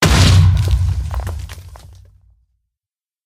苦力怕：爆炸
苦力怕在爆炸播随机放这些音效
Minecraft_Creeper_explosion2.mp3